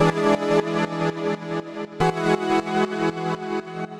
Index of /musicradar/sidechained-samples/120bpm
GnS_Pad-dbx1:8_120-E.wav